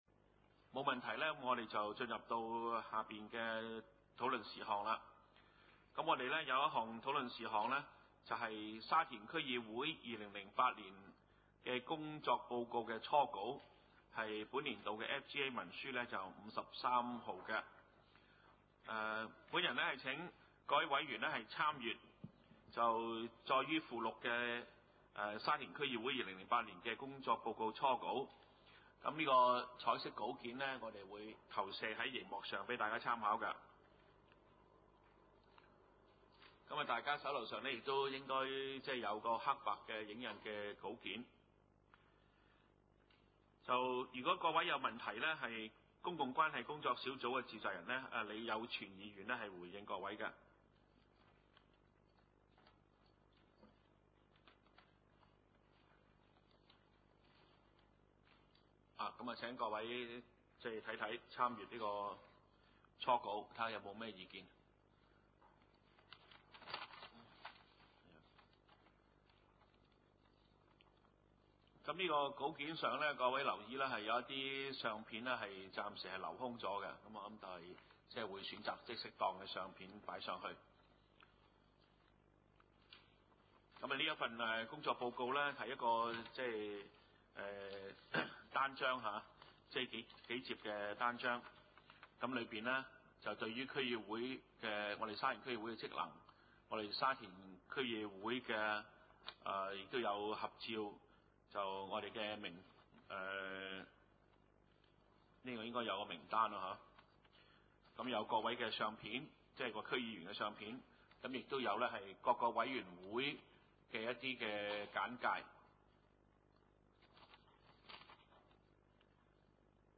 : 沙田區議會會議室